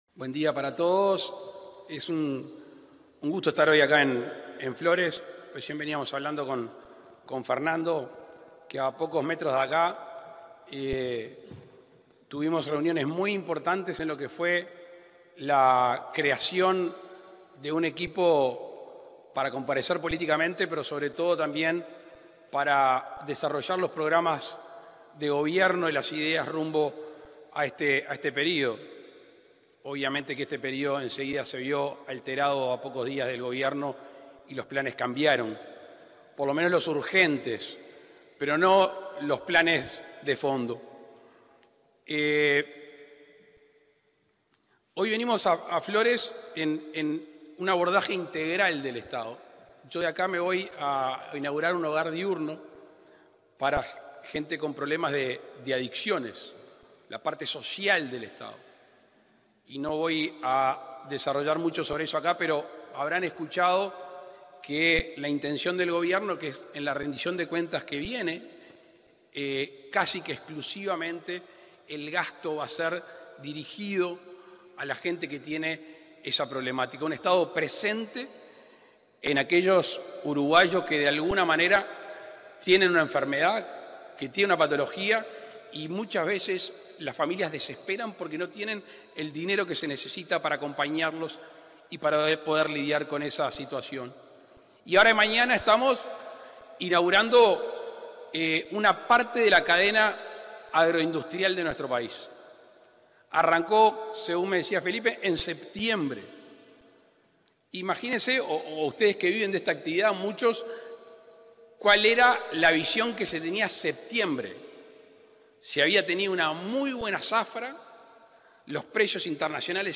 Palabras del presidente de la República, Luis Lacalle Pou, en Flores
Palabras del presidente de la República, Luis Lacalle Pou, en Flores 02/06/2023 Compartir Facebook X Copiar enlace WhatsApp LinkedIn En la ceremonia de inauguración de una planta de raciones en la Unión Rural de Flores, este 2 de junio, se expresó el presidente de la República, Luis Lacalle Pou.